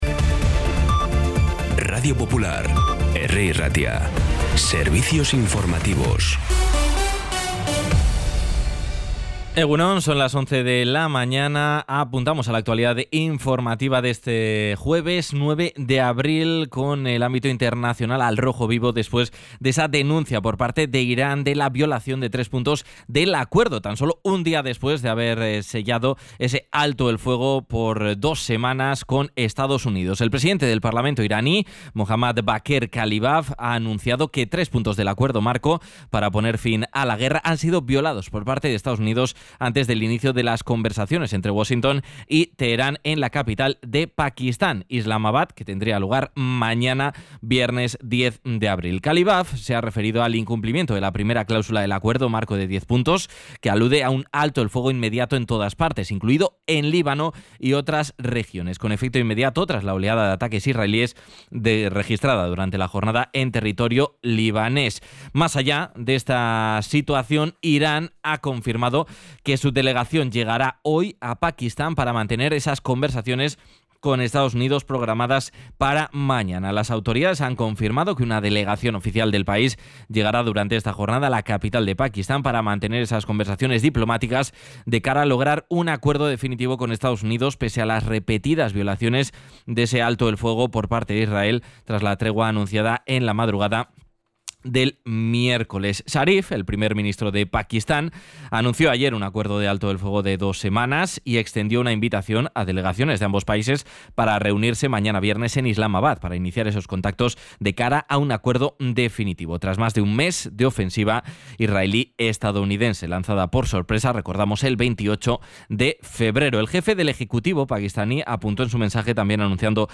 La última hora más cercana, de proximidad, con los boletines informativos de Radio Popular.
Los titulares actualizados con las voces del día. Bilbao, Bizkaia, comarcas, política, sociedad, cultura, sucesos, información de servicio público.